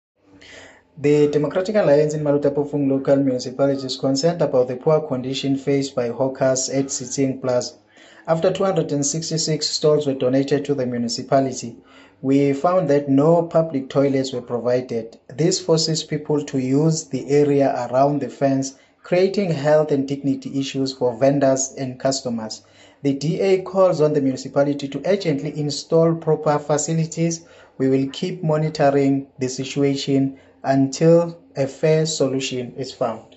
English soundbite by Cllr Richard Khumalo,